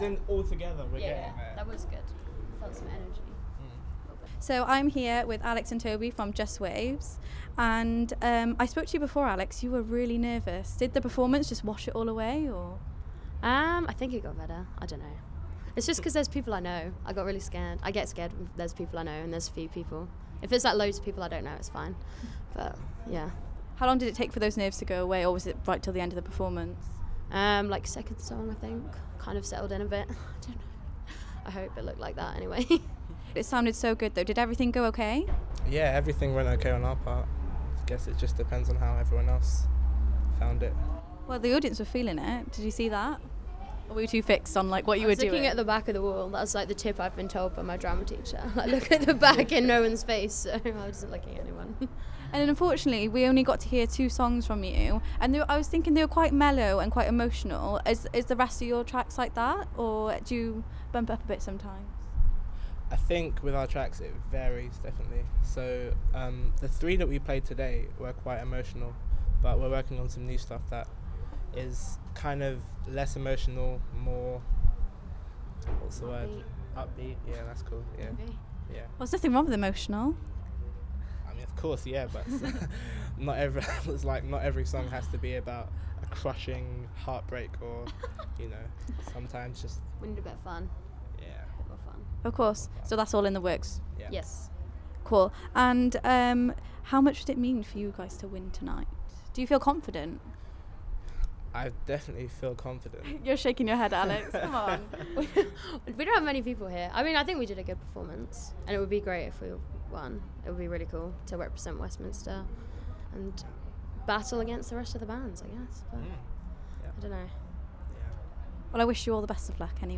iSessions Westminster: Charity Battle of the Bands#iSessionsWestminster
Smoke Radio went along to this years iSessions and caught up with all the bands and performers after their set.